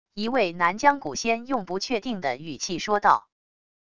一位南疆蛊仙用不确定的语气说道wav音频